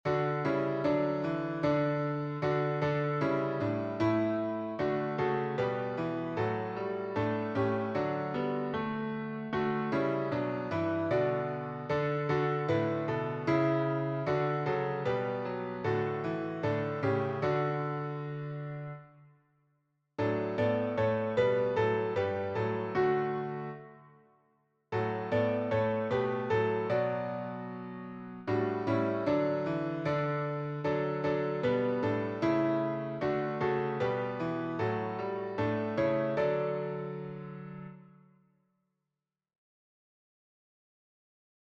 This is a SATB arrangement for choir and congregation. The organ and congregation use the hymnal and the choir sings the arrangement on one or more verses. This could also work well with strings.
Voicing/Instrumentation: SATB , Cello Duet/Cello Ensemble Member(s) , Violin Duet/Violin Ensemble Member(s) We also have other 86 arrangements of " Come, Thou Fount of Every Blessing ".
Choir with Congregation together in certain spots